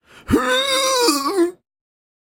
get-sick.ogg.mp3